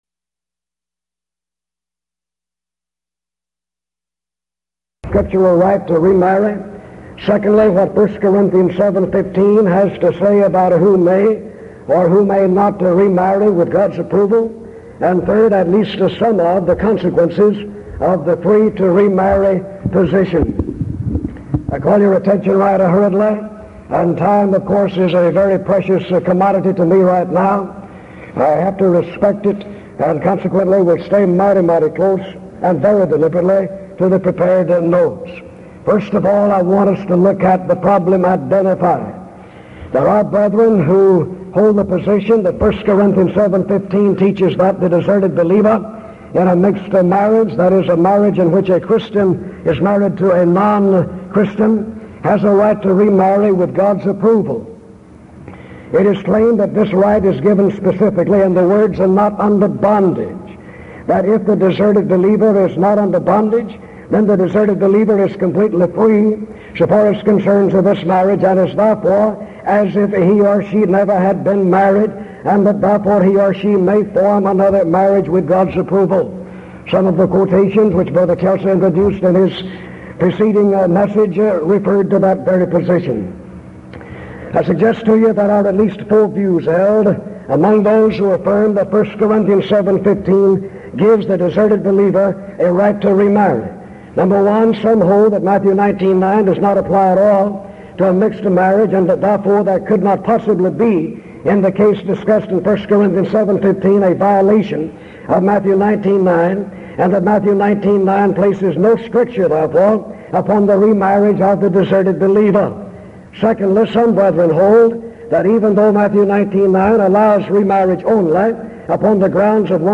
Event: 1982 Denton Lectures Theme/Title: Studies in 1 Corinthians